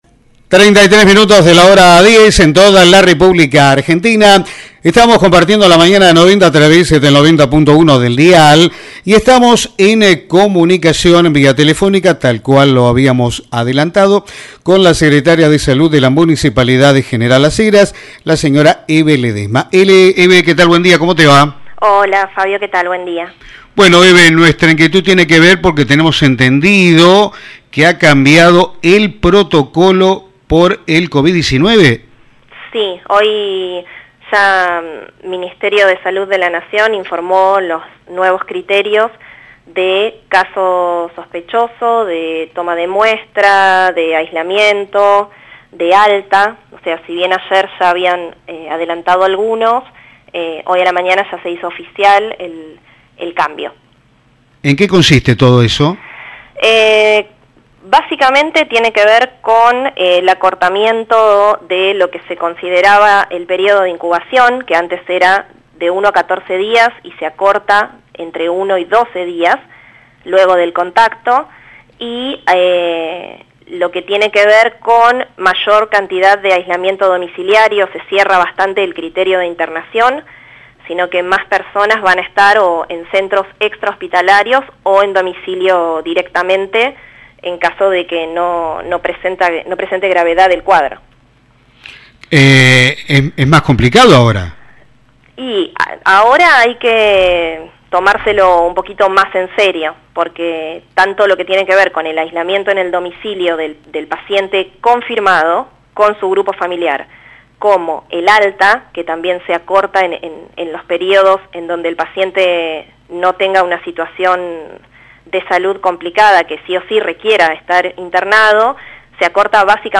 En comunicación telefónica con FM san Gabriel, la Secretaria de Salud, Hebe Ledesma contaba las modificaciones en el el protocolo de coronavirus.